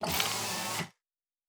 pgs/Assets/Audio/Sci-Fi Sounds/Mechanical/Servo Small 7_2.wav at master
Servo Small 7_2.wav